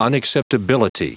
Prononciation du mot unacceptability en anglais (fichier audio)
Prononciation du mot : unacceptability